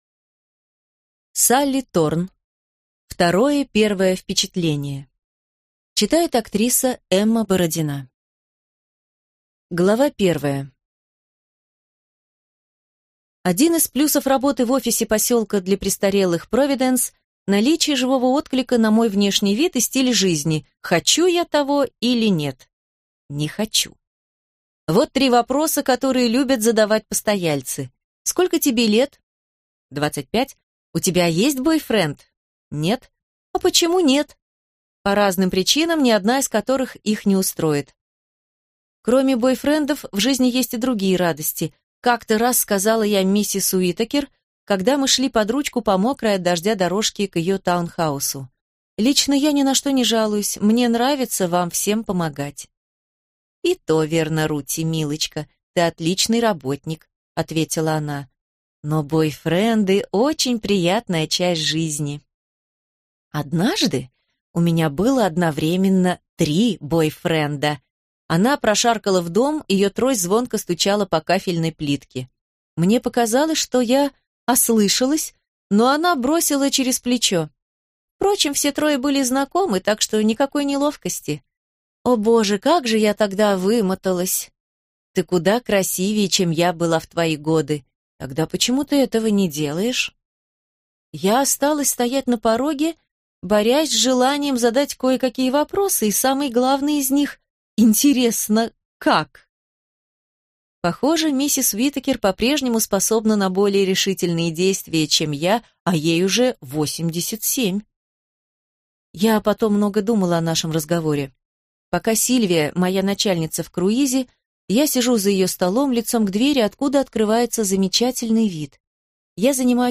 Аудиокнига Второе первое впечатление | Библиотека аудиокниг